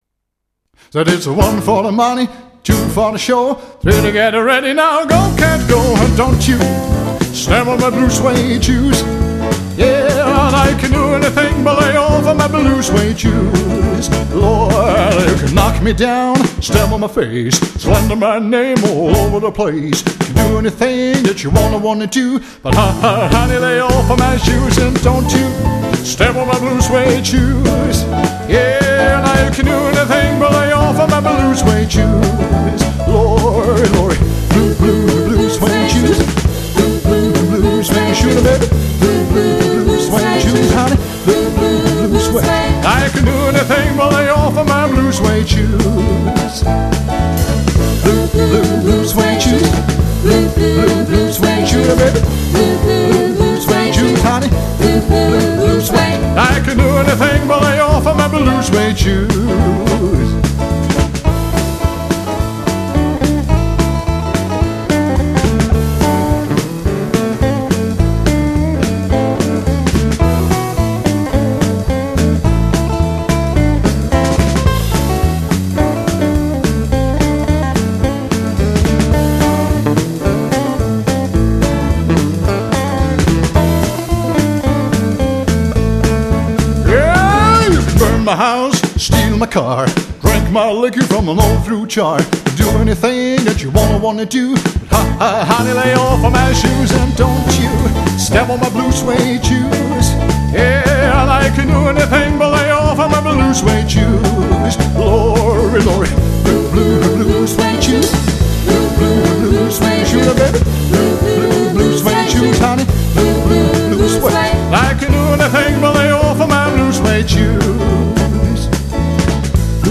Begleitet wird er von einem kleinen Frauenchor
Piano
Gitarre
Bass
Schlagzeug